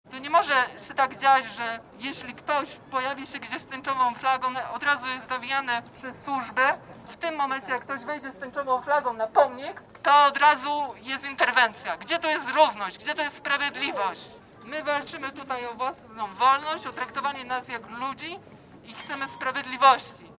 Demonstracja zgromadzenia LGBT w pasażu Schillera - Radio Łódź